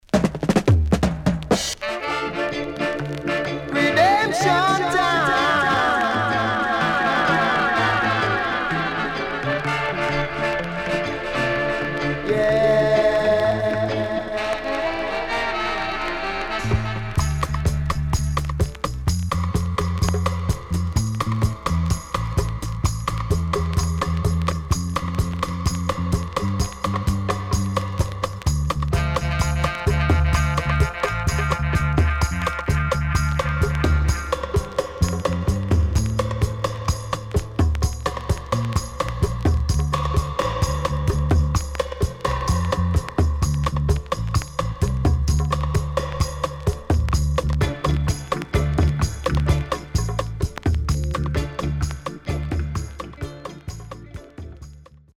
77年 Killer Stepper & Dubwise
SIDE A:所々チリノイズがあり、少しプチノイズ入ります。